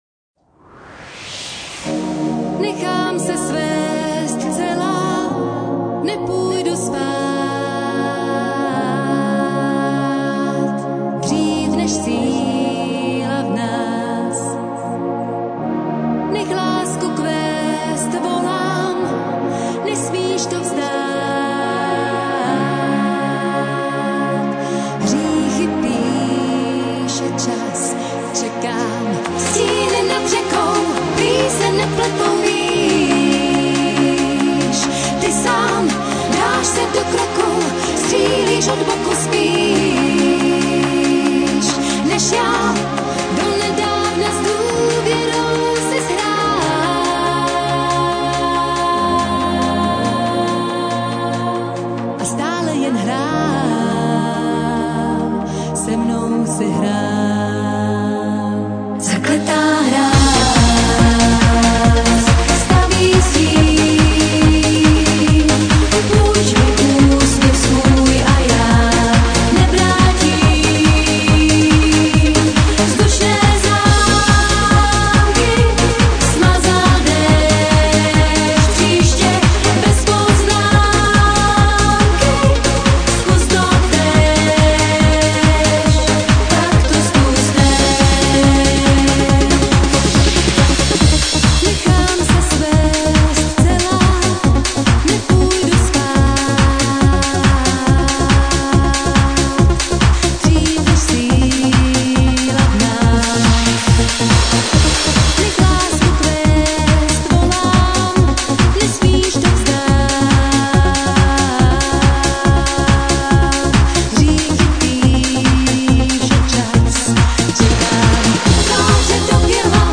Nahráno v: Studiích Drama a studio Svengali